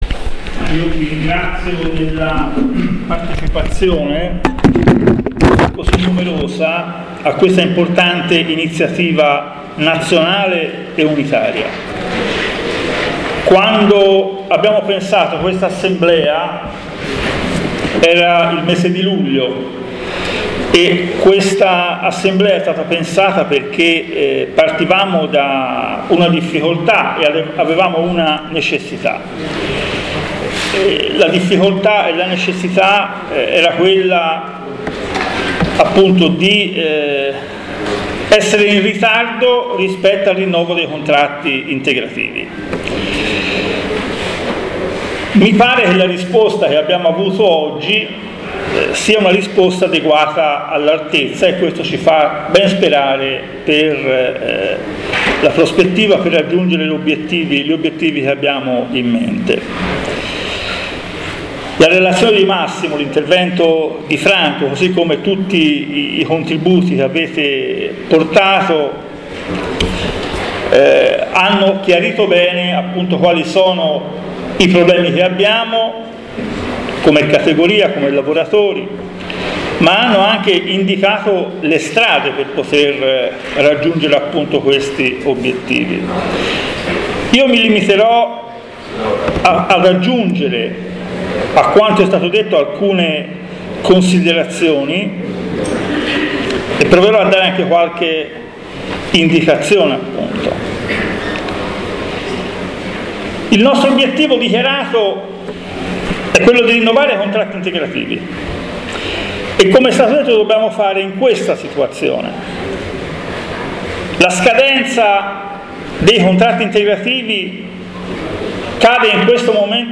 Edilizia, assemblea nazionale quadri e delegati Fillea Filca Feneal su rinnovi contratti integrativi